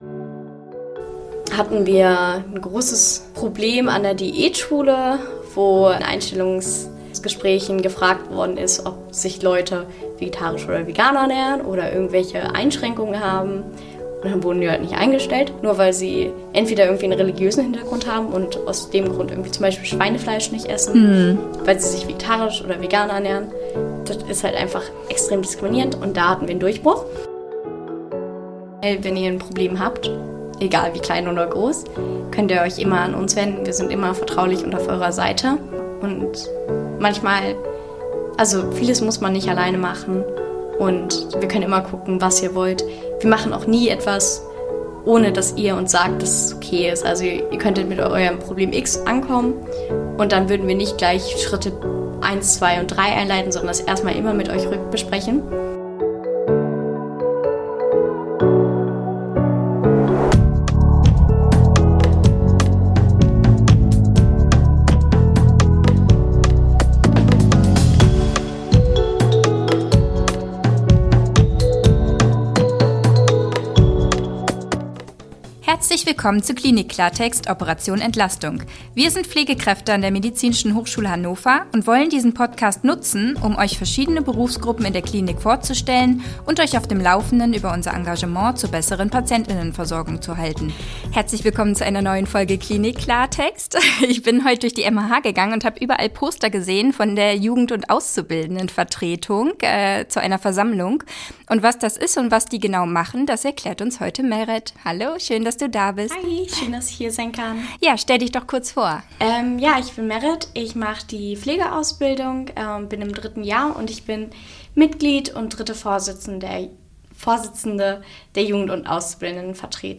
Jugend- und Auszubildendenvertretung (JAV) – Ein Interview